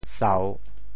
哨 部首拼音 部首 口 总笔划 10 部外笔划 7 普通话 shào 潮州发音 潮州 sao3 文 sa3 潮阳 sao3 澄海 sao3 揭阳 sao3 饶平 sao3 汕头 sao3 中文解释 哨〈形〉 (形声。
sau3.mp3